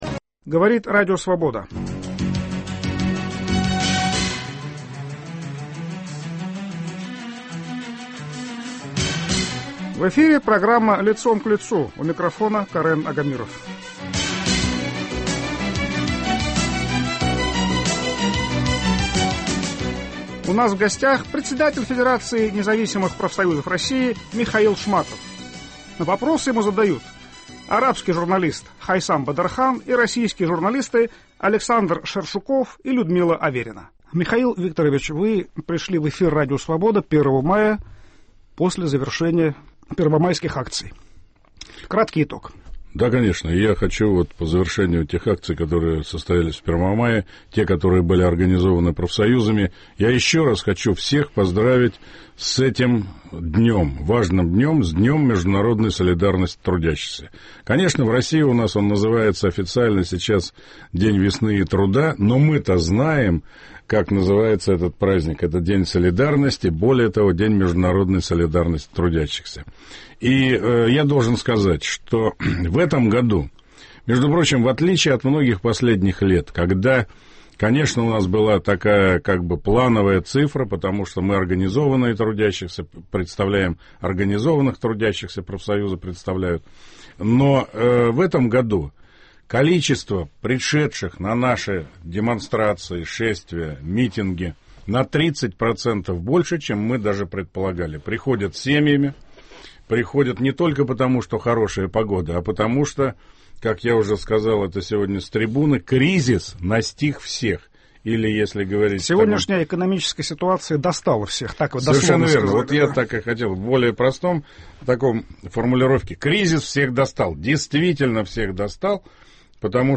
Лидер в той или иной сфере общественной жизни - человек известный и информированный - под перекрестным огнем вопросов трех журналистов: российского, иностранного и ведущего "Свободы".